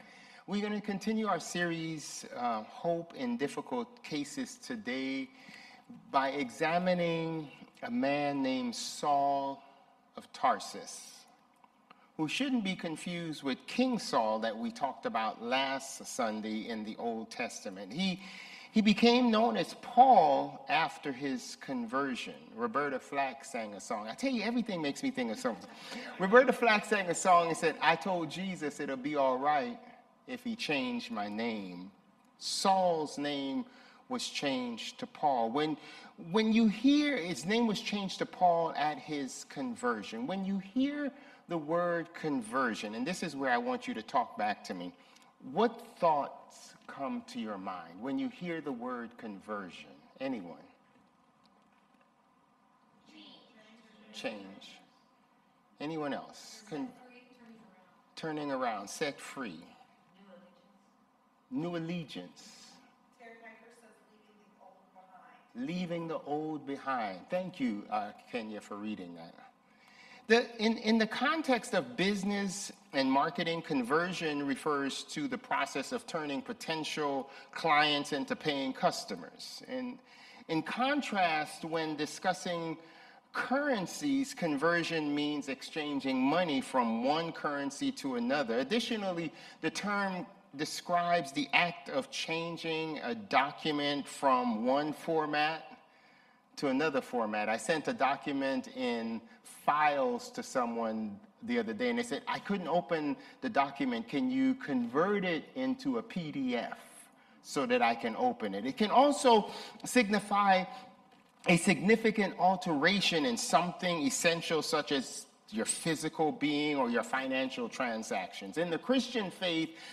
Sermons | Bethel Lutheran Church
August 24 Worship